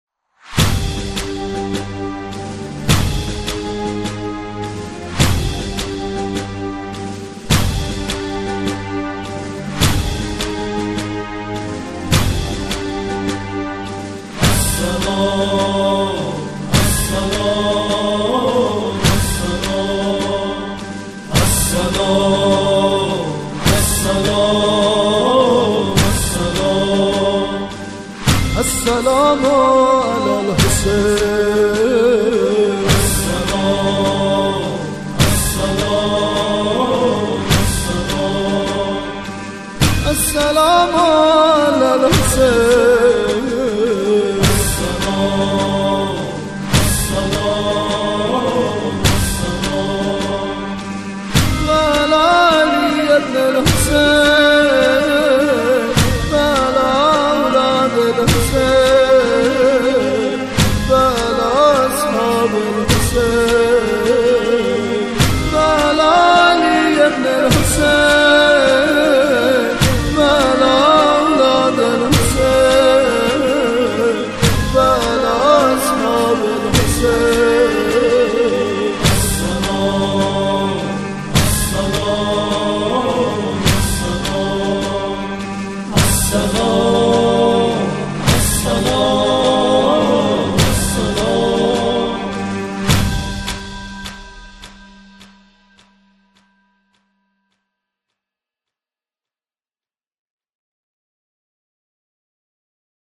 این قطعه، جمعخوانی جملات اول زیارت عاشورا است.